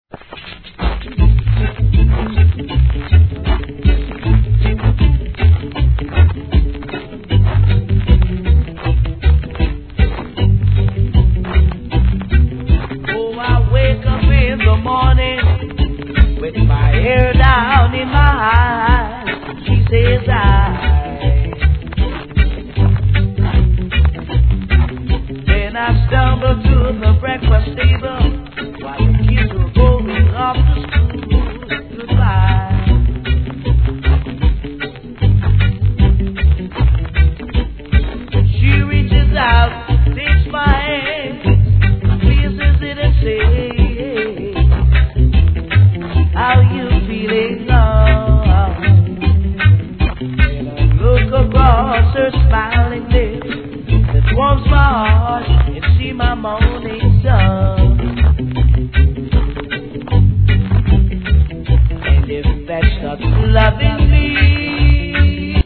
C (周期的なノイズ)
REGGAE